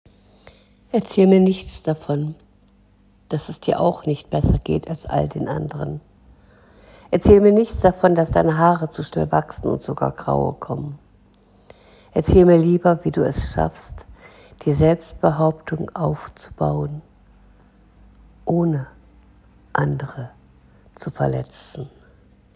Erzähl mir nichts - ein Gedicht